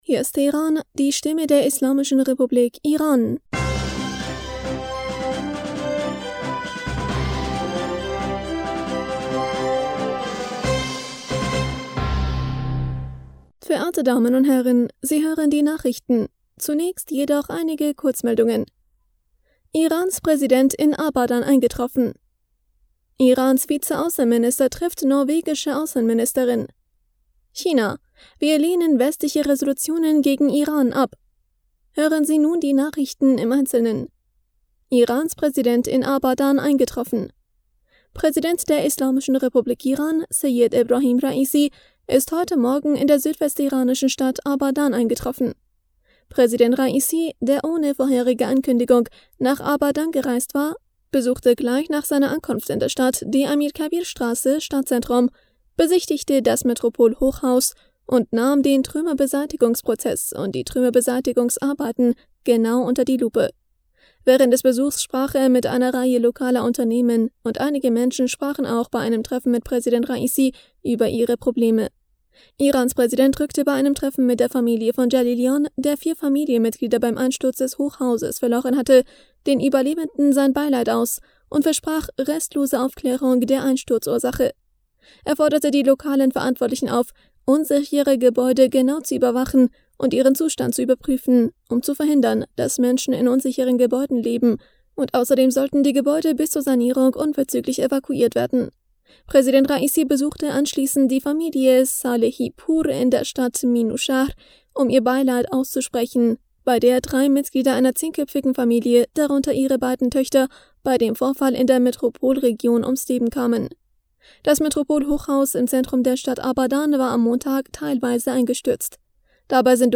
Nachrichten vom 3. Juni 2022
Die Nachrichten von Freitag dem 3. Juni 2022